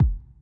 000-kick.wav